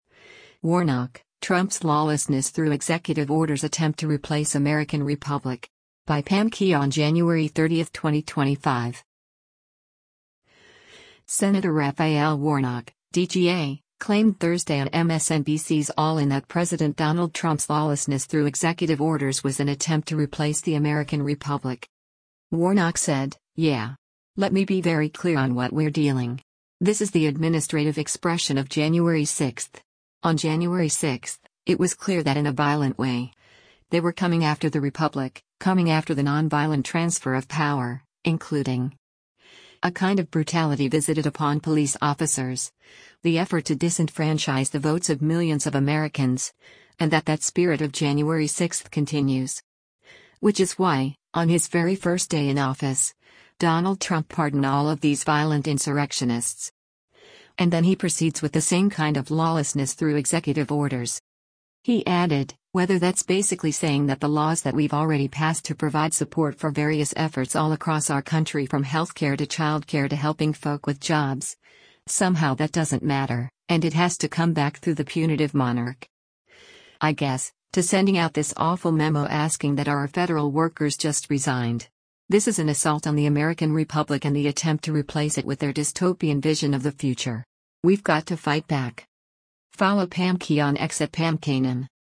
Senator Raphael Warnock (D-GA) claimed Thursday on MSNBC’s “All In” that President Donald Trump’s “lawlessness through executive orders” was an attempt to replace the American republic.